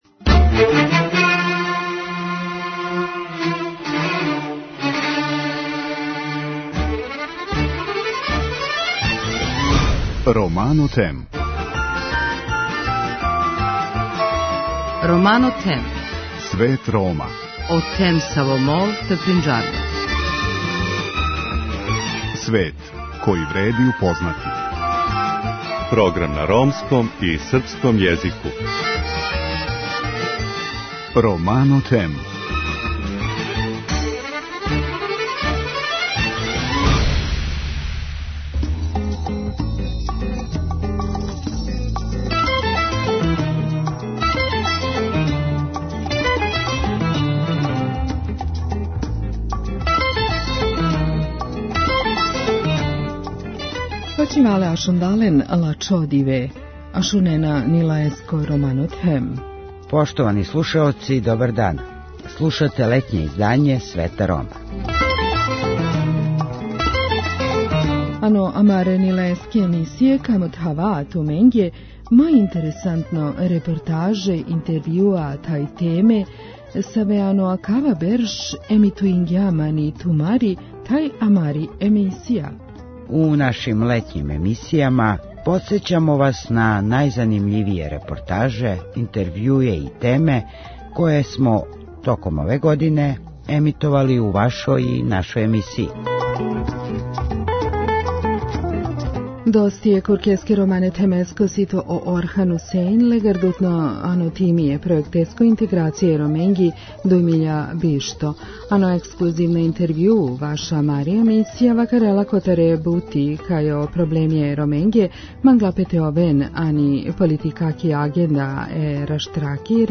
У ексклузивном интервјуу за нашу емисију говори о напорима да се проблеми Рома ставе на политичку агенду држава Западног Балкана, о Познанској декларацији, о резултатима социо-економске интеграције али и открива на чему ће радити у Фази 3 овог пројекта.